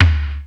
E2+ TOM 1.wav